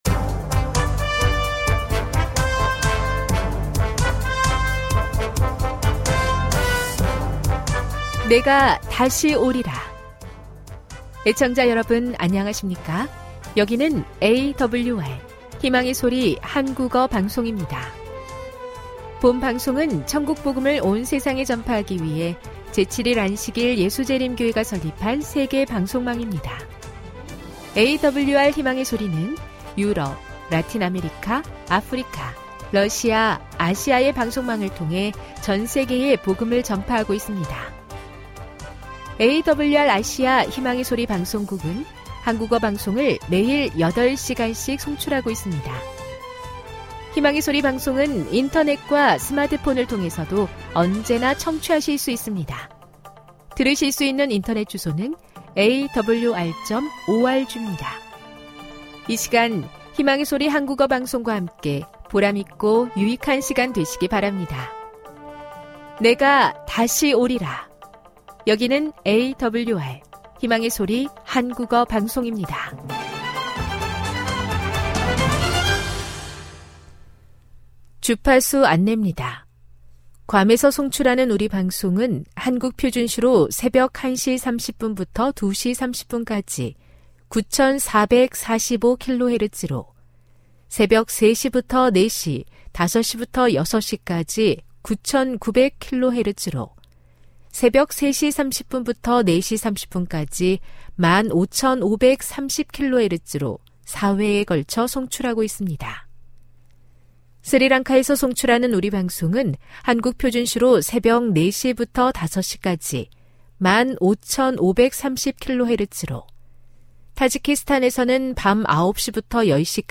1 설교, 걸어서 성경속으로 58:08